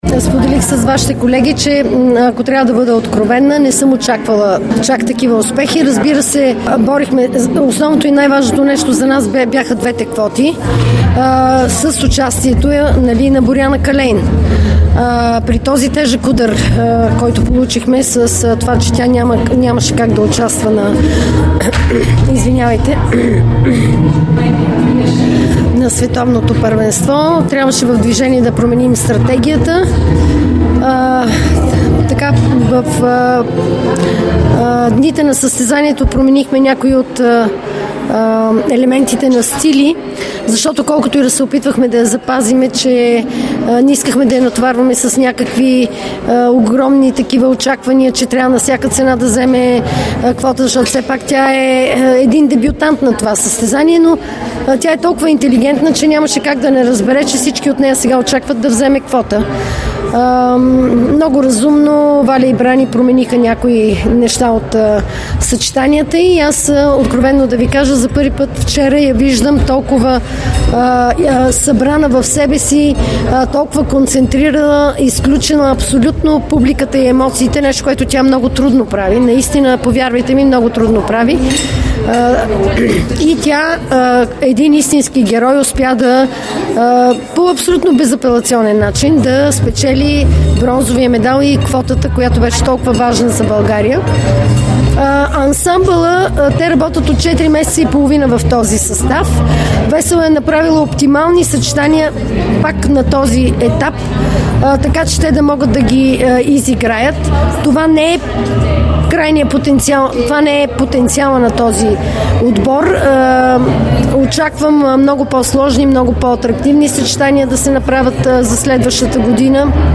Президентът на българската федерация по художествена гимнастика Илиана Раева говори след края на Световното първенство, провело се в „Арена Армеец“.